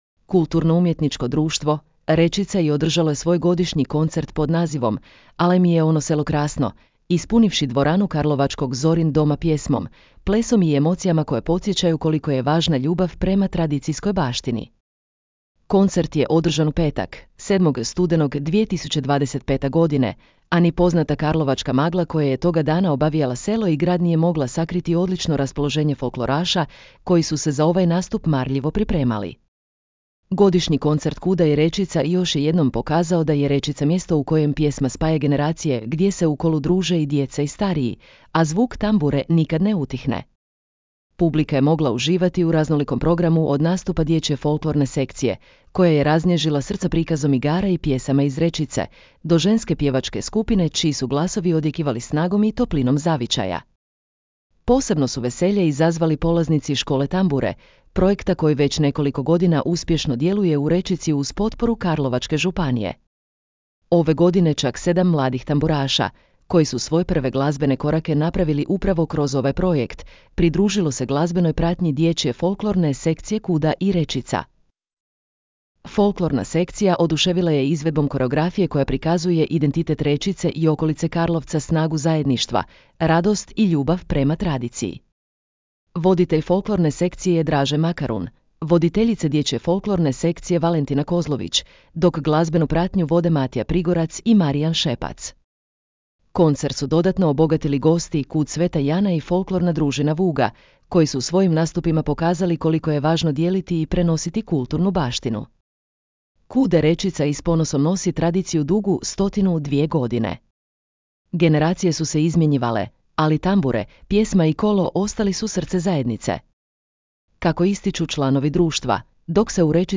Godišnji koncert KUD-a “Rečica” još je jednom pokazao da je Rečica mjesto u kojem pjesma spaja generacije – gdje se u kolu druže i djeca i stariji, a zvuk tambure nikad ne utihne.
Publika je mogla uživati u raznolikom programu – od nastupa dječje folklorne sekcije, koja je raznježila srca prikazom igara i pjesama iz Rečice, do ženske pjevačke skupine čiji su glasovi odjekivali snagom i toplinom zavičaja.
Generacije su se izmjenjivale, ali tambure, pjesma i kolo ostali su srce zajednice.